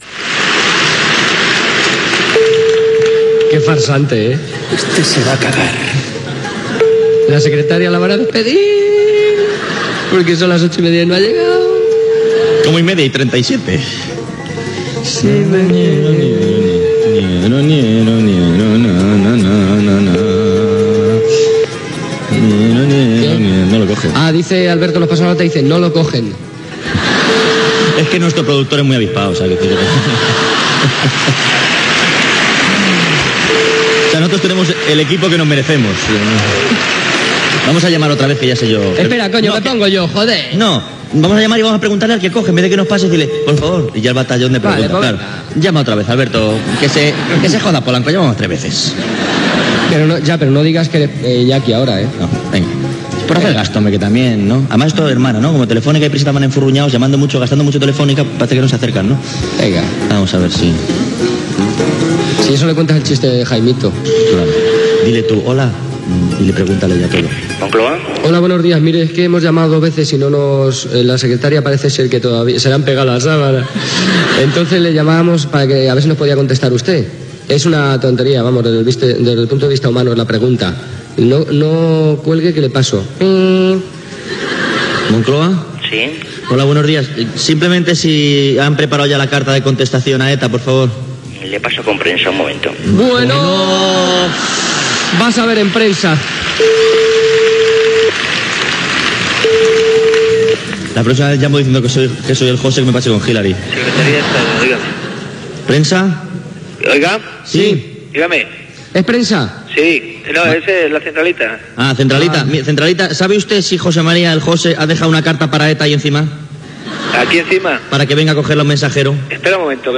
Fragment d'una trucada telefònica al Palacio de la Moncloa.
Entreteniment
FM